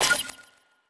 s014_GachaOpen.wav